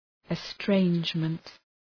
Shkrimi fonetik{ı’streındʒmənt}